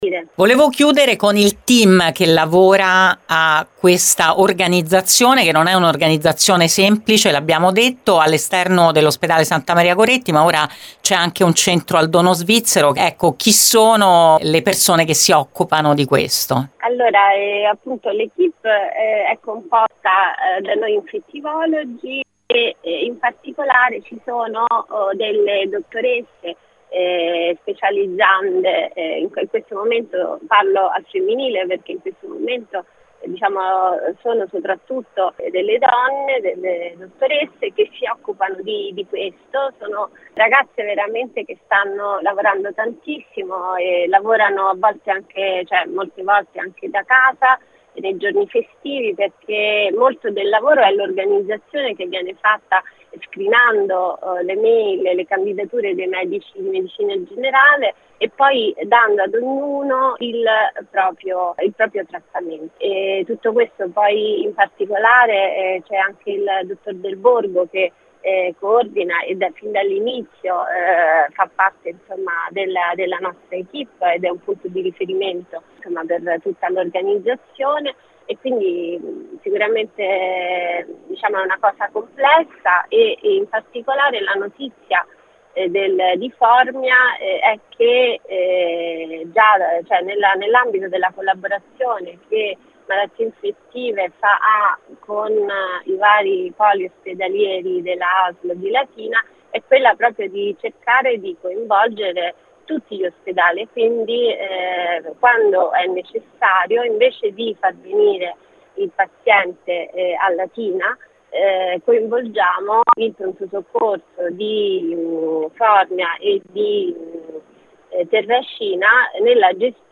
Per fare il punto ne abbiamo parlato con la professoressa